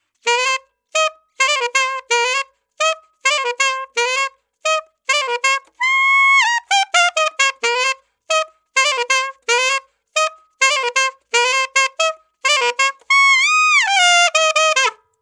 房屋阿尔托环线3
Tag: 130 bpm House Loops Woodwind Loops 1.26 MB wav Key : Unknown